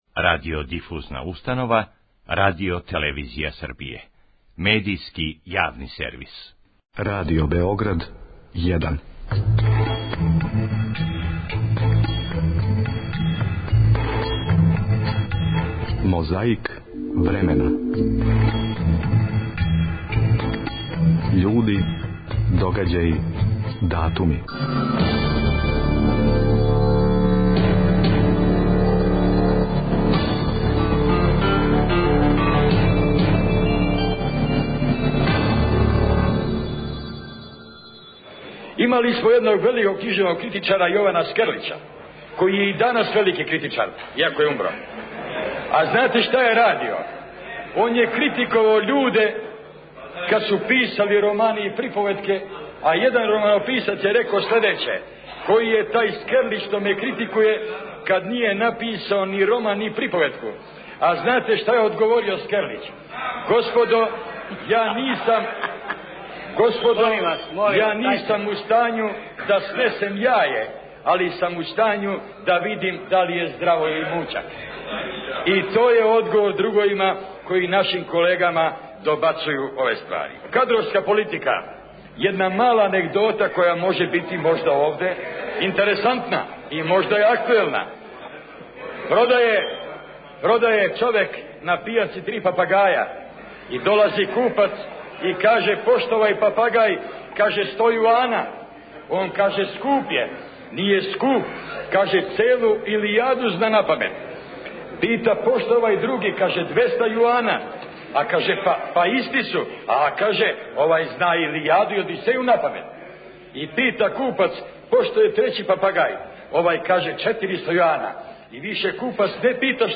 Највећи син наших народа и народности Јосип Броз Тито, на конференцији за новинаре домаће и стране, одговарао је стрпљиво на свако постављено питање.
Подсећа на прошлост (културну, историјску, политичку, спортску и сваку другу) уз помоћ материјала из Тонског архива, Документације и библиотеке Радио Београда.